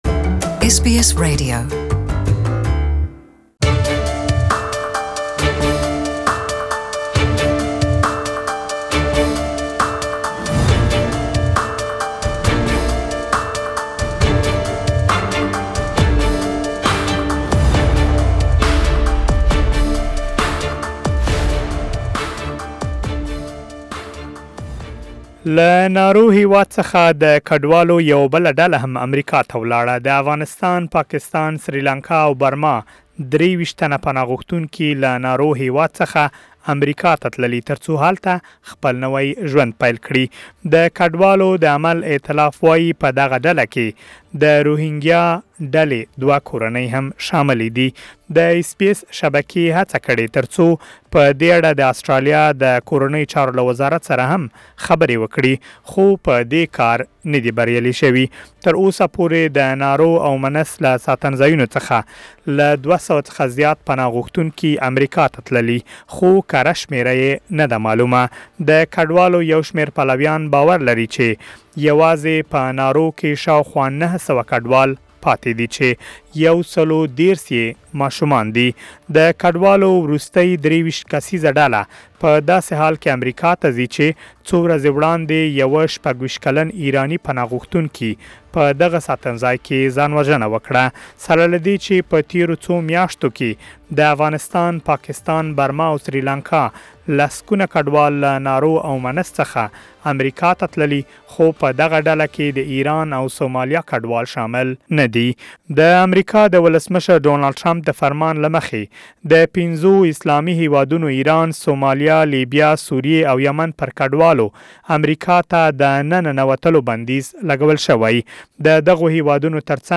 پدې اړه لاډېر مالومات په راپور کې واورئ